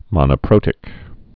(mŏnə-prōtĭk)